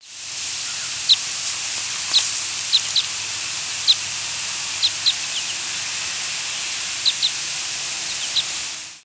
Red Crossbill flight calls
Type 3 in flight.